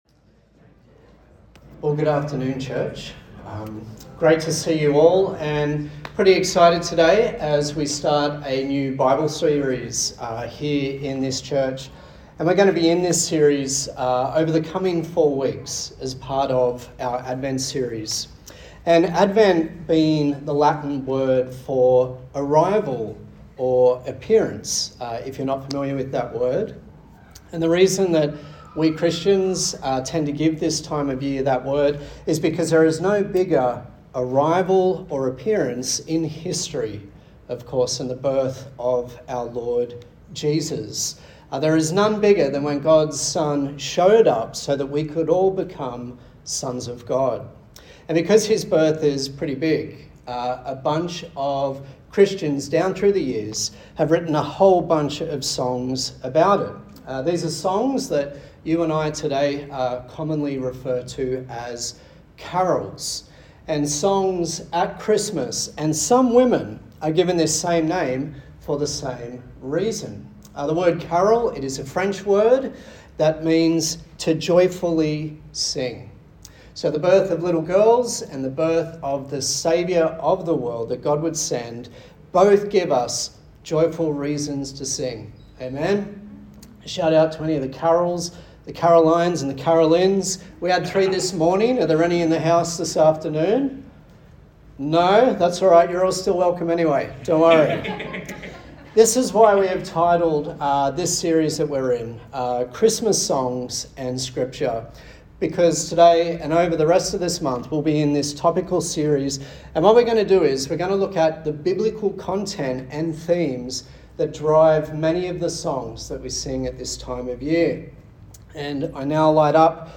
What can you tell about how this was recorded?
Advent 2024 Passage: Luke 2:1-21 Service Type: Sunday Service